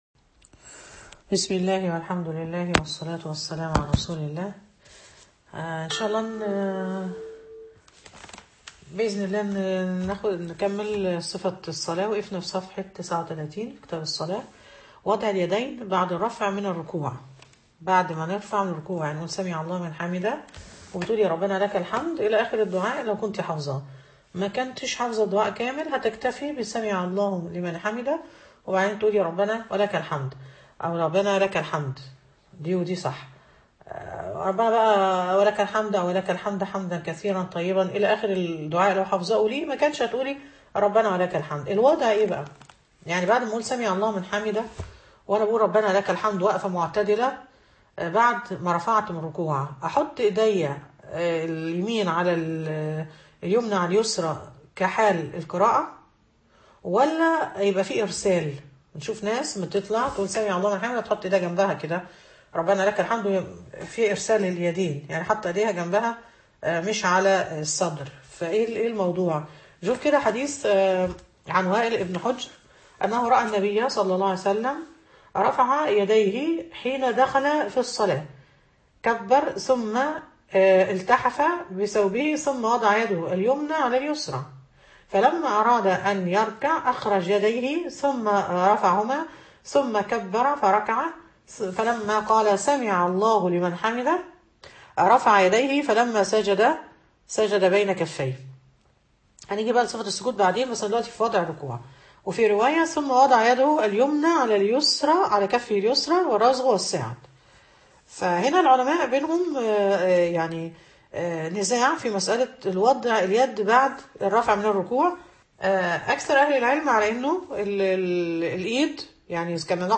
فقه الصلاة_المحاضرة السابعة